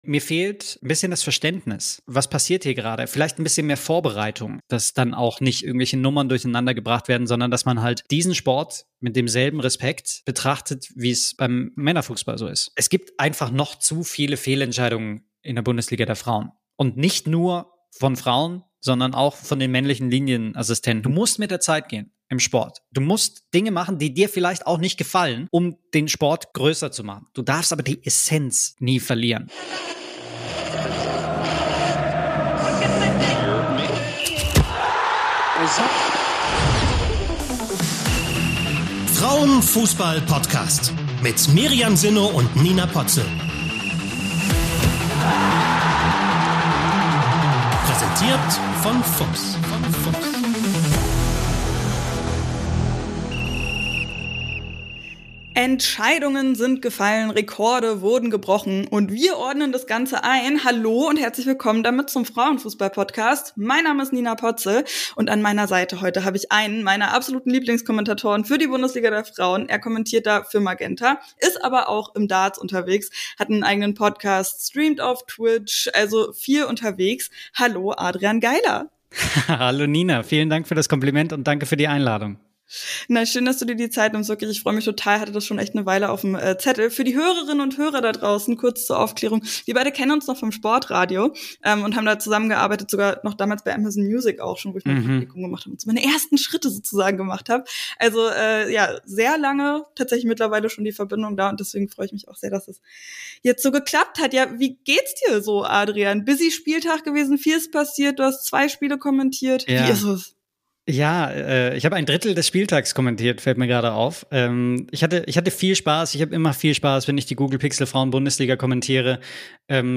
Euer Podcast zum Fußball der Frauen. Bundesliga, DFB-Pokal, Länderspiele - hier hört ihr Analysen, News und Interviews